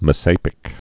(mə-sāpĭk)